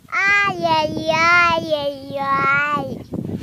детский голос
звук ругания
Рингтон для телефона на смс.